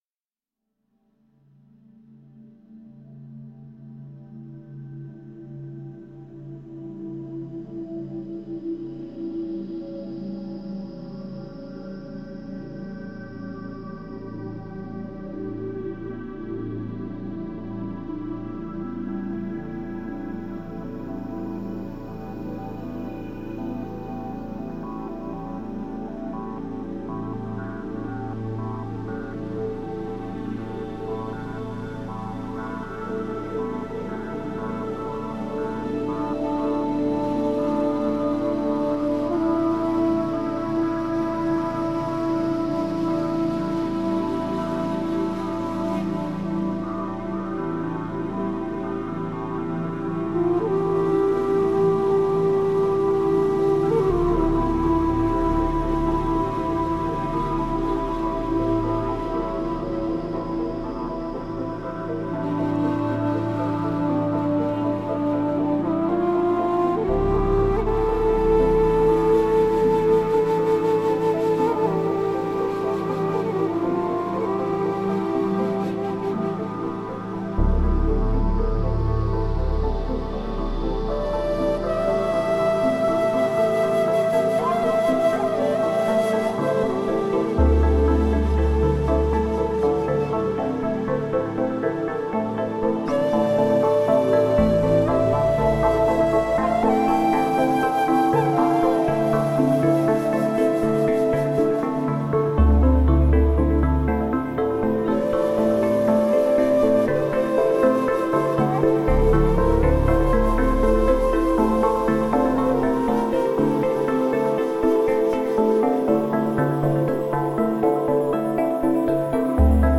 Groundbreaking ambient and dark-ambient.